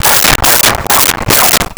Dog Barking 03
Dog Barking 03.wav